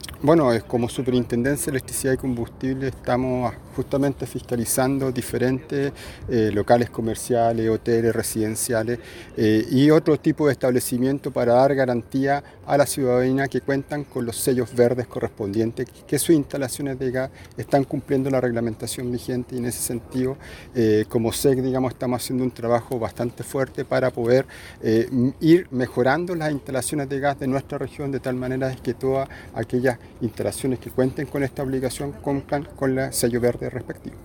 Director-SEC-Patricio-Velásquez.mp3